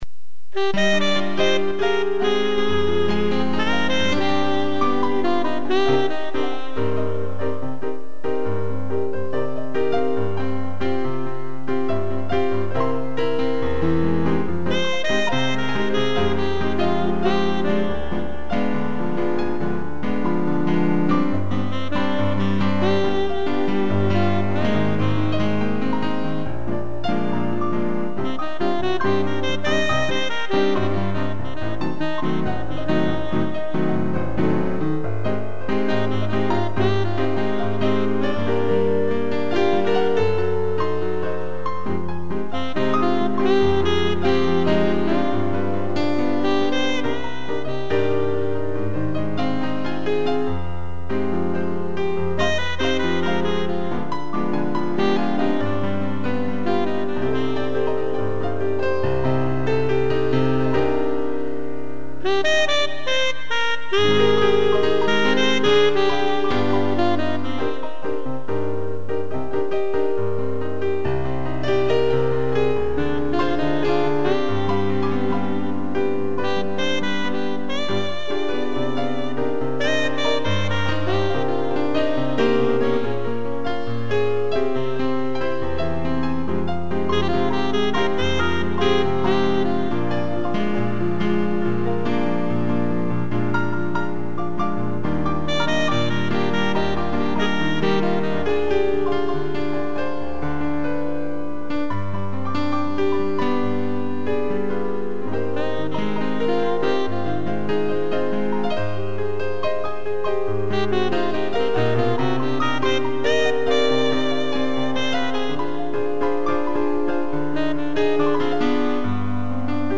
2 pianos e sax
instrumental